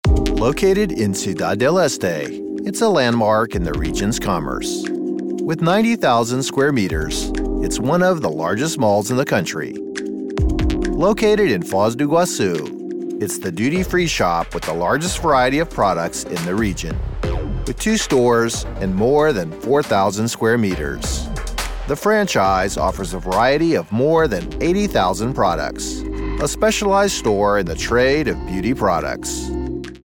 • For voiceovers – a home studio with acoustic treatment, CM25 MkIII condenser microphone, Focusrite Scarlett Si2 interface, Adobe Audition Software.
Standard Corporate